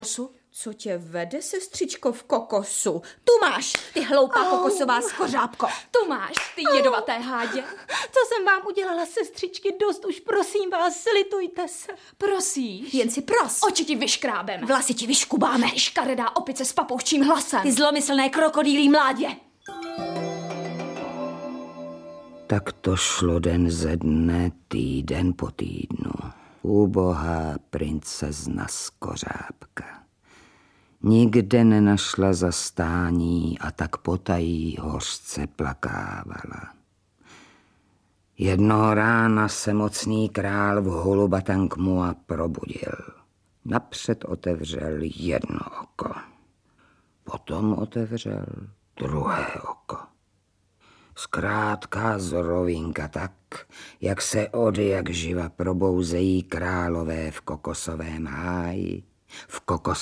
Audiobook
Read: Věra Galatíková